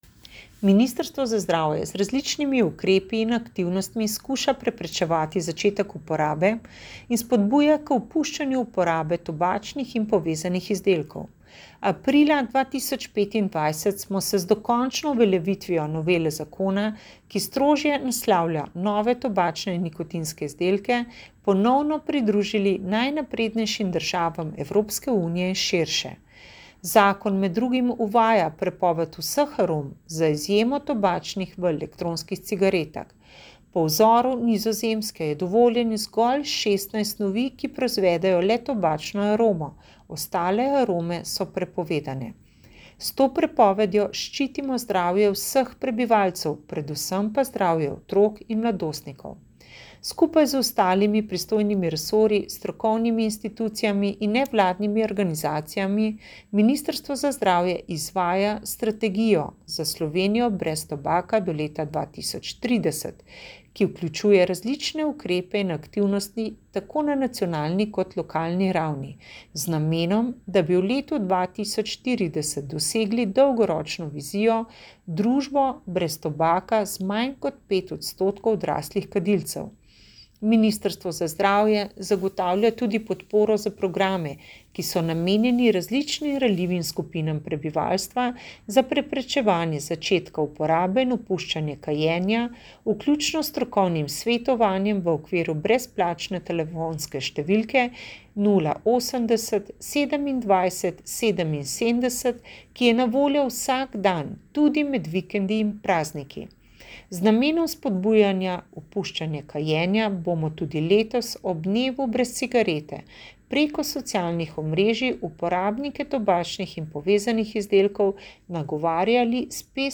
Avdio izjave: